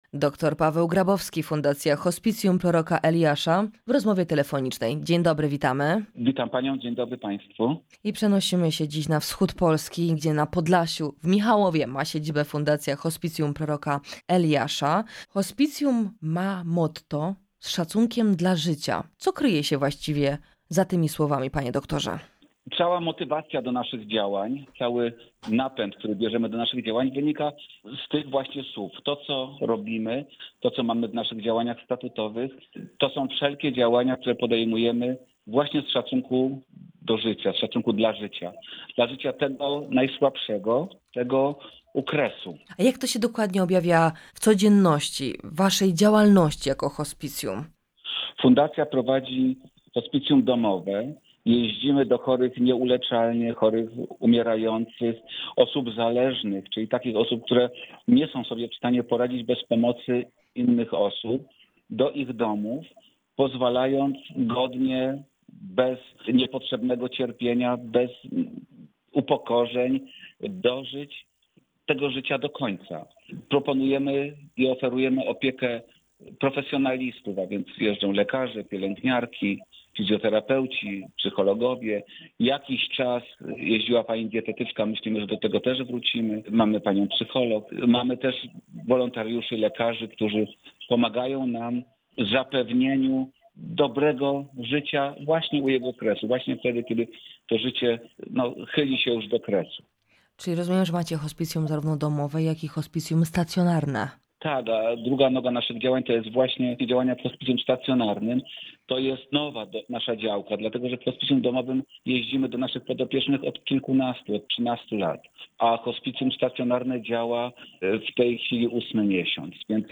O misje, wyzwaniach i pomocy dla hospicjum opowiada na antenie Radia Rodzina